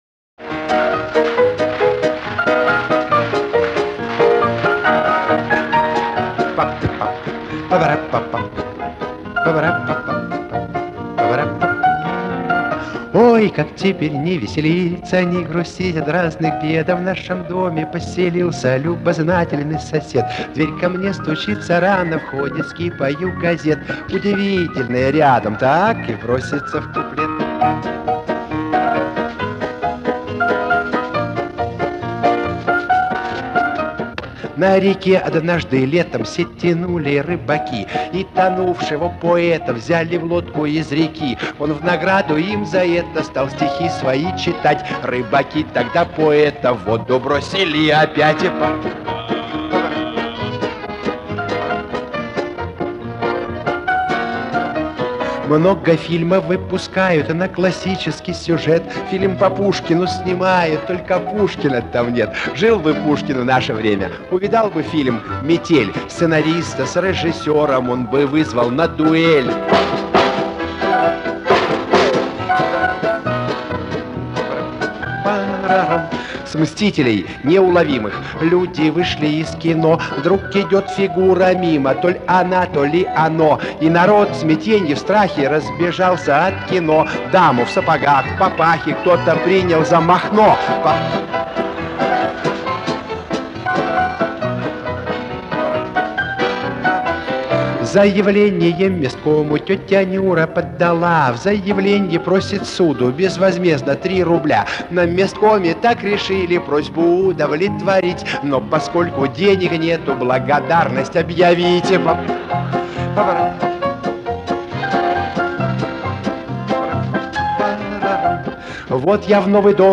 Песня, частушка,  хорошо известная.